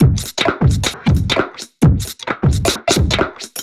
Index of /musicradar/uk-garage-samples/132bpm Lines n Loops/Beats
GA_BeatDSweepz132-03.wav